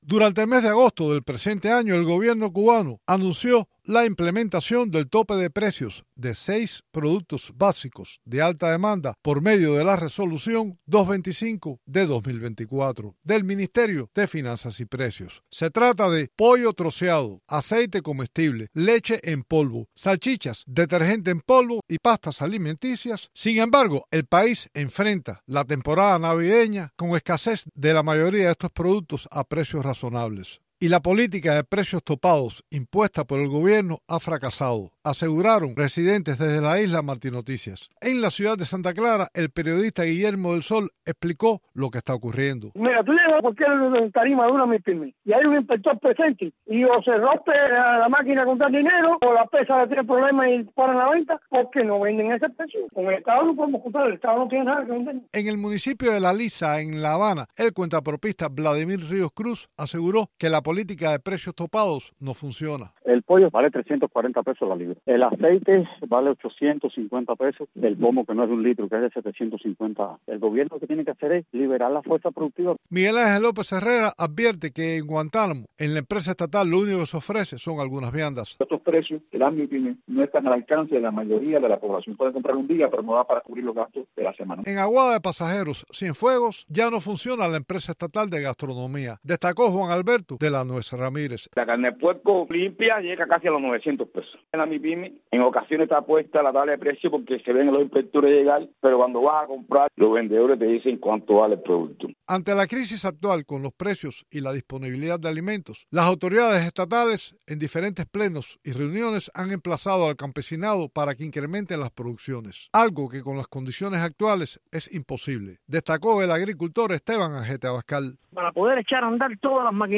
Cubanos entrevistados por Martí Noticias señalan la ineficiencia del gobierno como la principal causa de la escasez de alimentos.